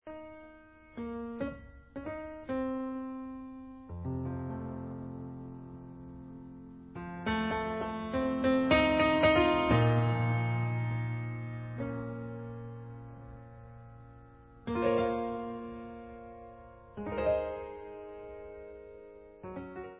sledovat novinky v oddělení Jazz/Fusion